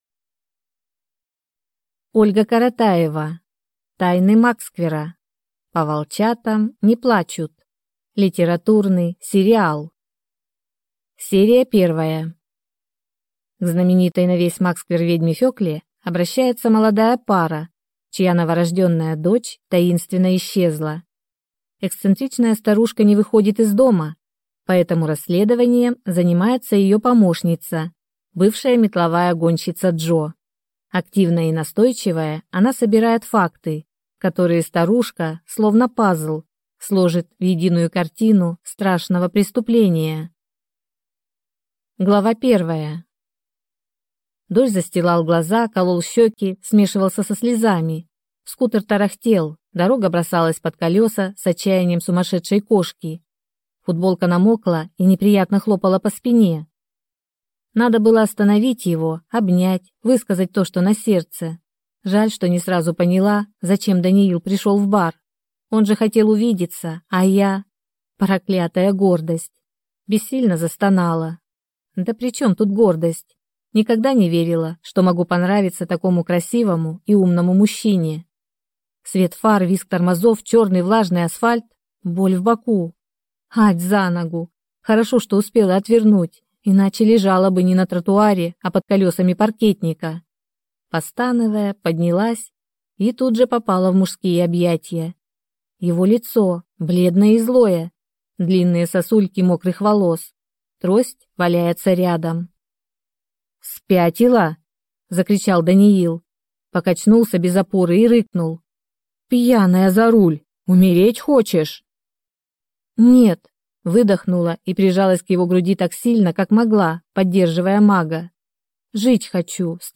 Аудиокнига Тайны Магсквера: По волчатам не плачут | Библиотека аудиокниг